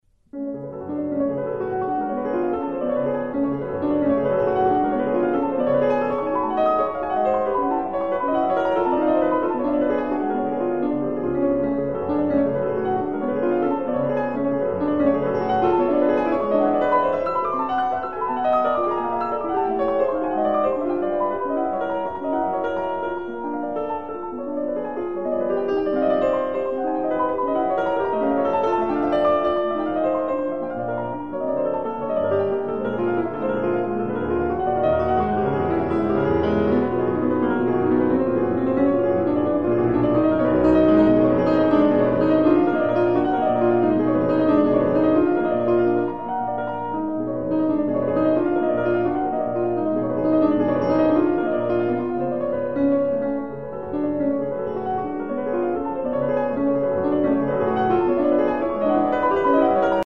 Fryderyk Chopin - Prelude Op. 28 - No 8 in in F sharp minor Molto agitato + midi file